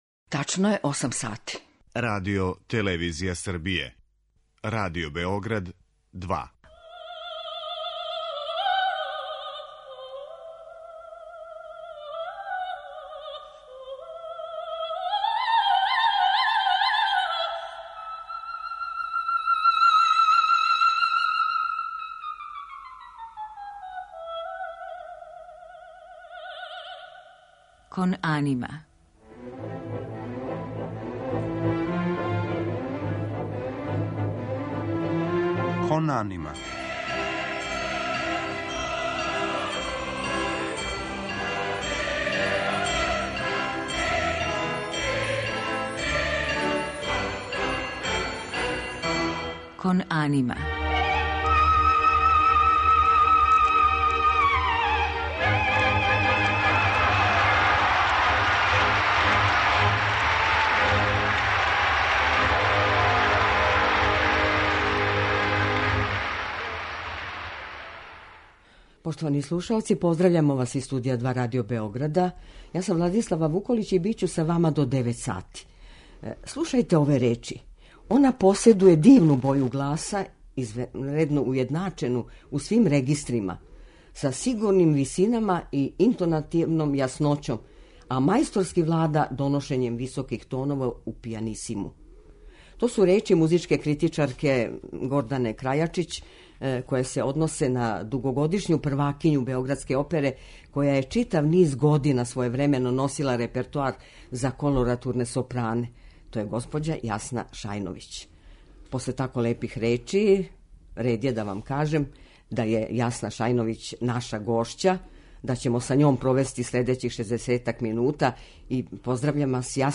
Музички фрагменти који ће бити представљени у емисији одабрани су из њеног богатог репертоарa за колоратурне сопране, и то из опера Росинија, Доницетија, Моцарта и Вердија.